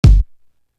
Carved In Stone Kick.wav